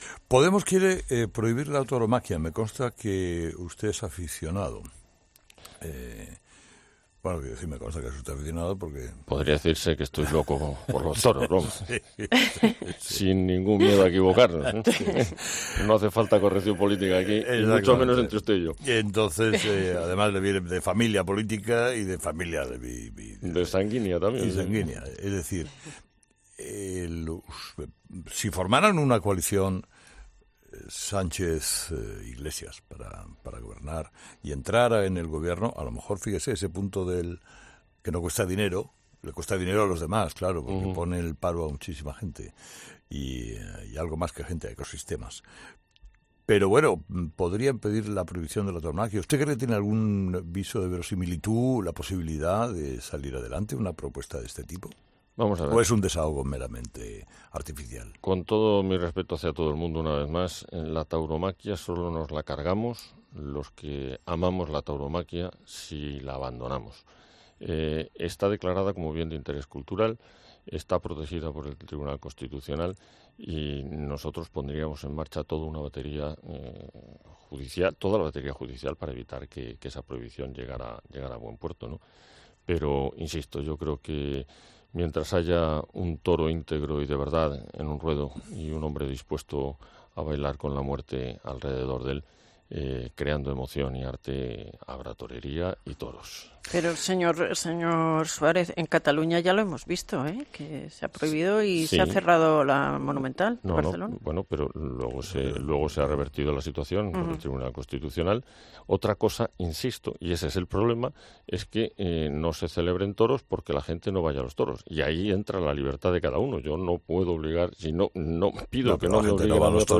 Una posible y futura coalición PSOE-Podemos con la idea de la formación morada de prohibir los toros tal y como propugna, ha sido abordada por Adolfo Suárez Illana este martes en Herrera en COPE a pregunta de Carlos Herrera.